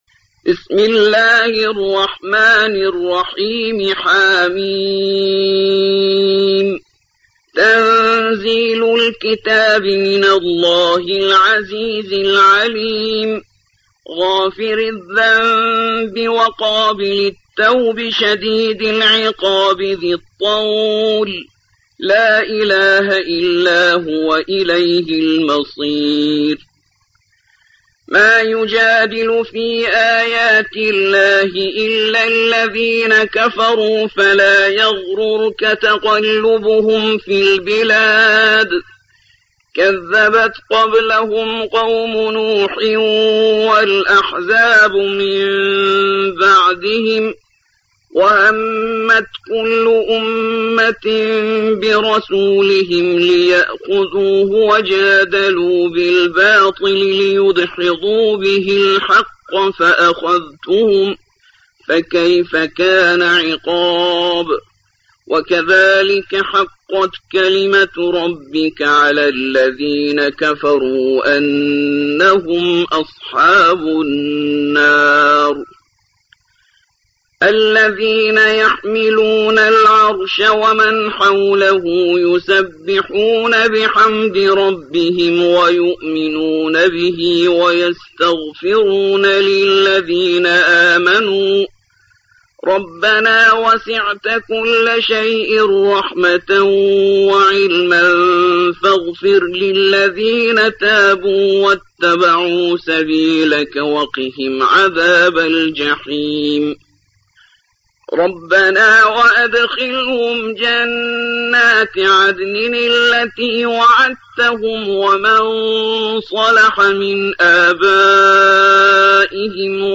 40. سورة غافر / القارئ